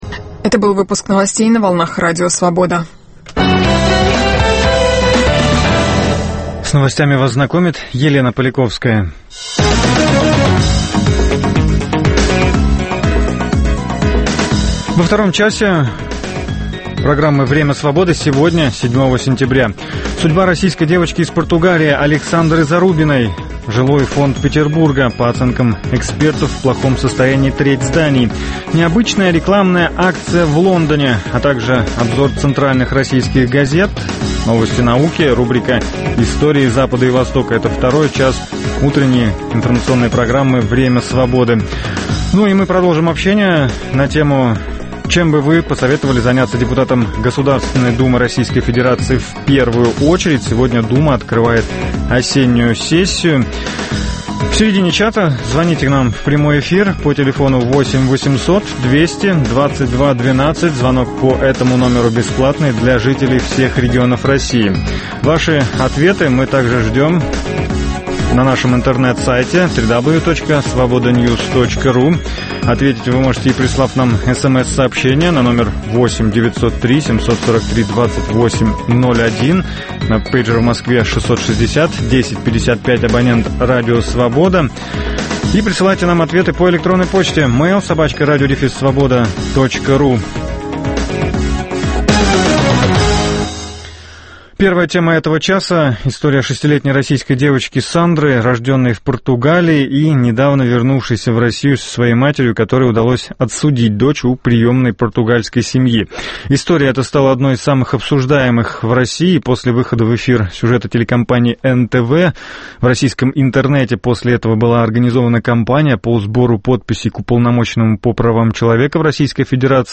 С 9 до 10 часов утра мы расскажем о том, что готовит нам начинающийся день. Представим панораму политических, спортивных, научных новостей, в прямом эфире обсудим с гостями и экспертами самые свежие темы нового дня, поговорим о жизни двух российских столиц.